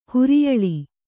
♪ huriyaḷi